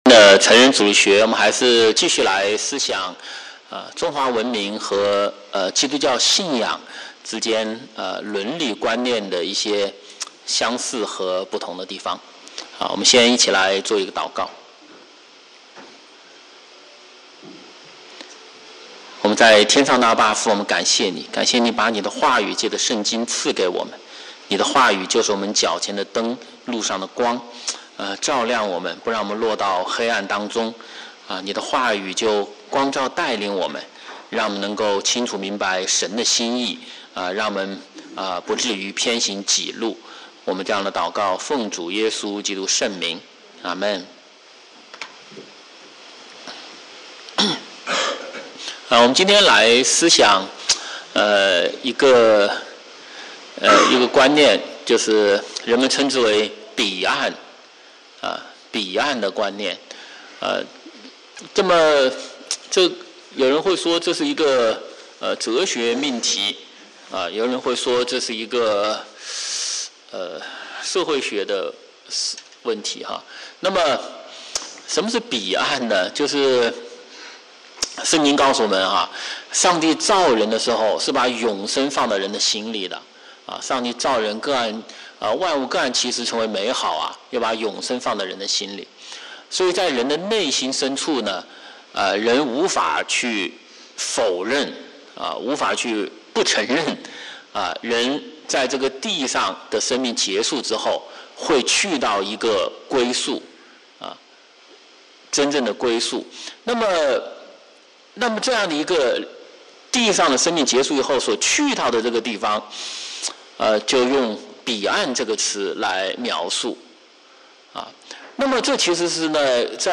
主日學